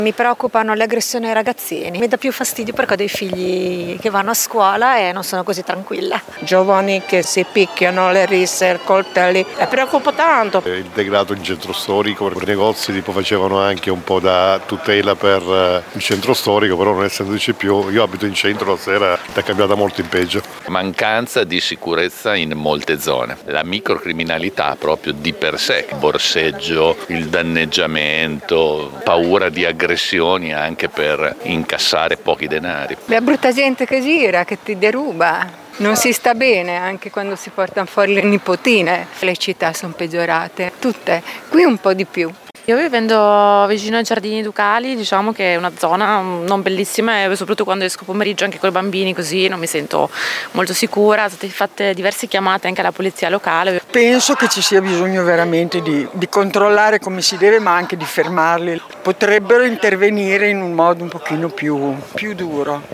Ed è proprio la criminalità giovanile a preoccupare di più i modenesi come testimoniano queste interviste: